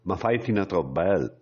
10 frasi in bergamasco sull’annata dell’Atalanta